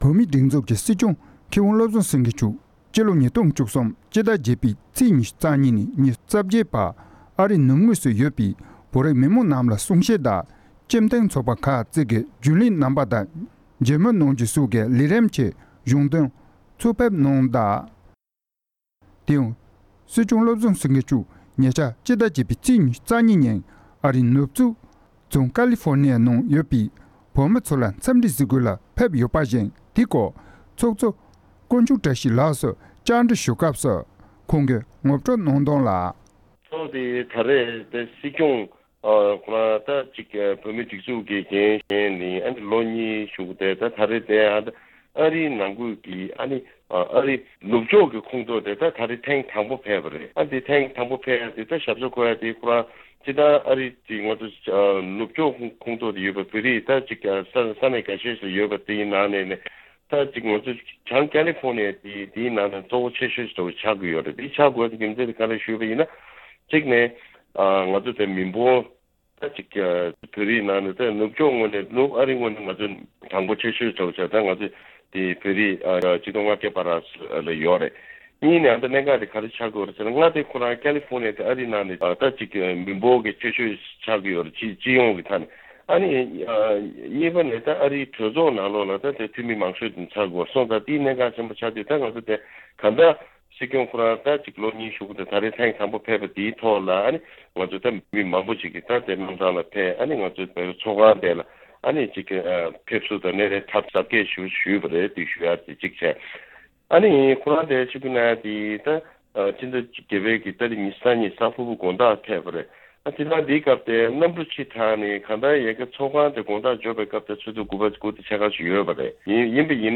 འབྲེལ་ཡོད་ས་གནས་བོད་རིགས་སྤྱི་མཐུན་ཚོགས་པའི་ཚོགས་གཙོ་སོགས་ལ་བཅར་འདྲི་ཞུས་ཏེ་ཕྱོགས་སྒྲིག་དང་སྙན་སྒྲོན་ཞུས་པར་གསན་རོགས་ཞུ༎